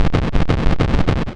Soundeffekte
broeckelig.wav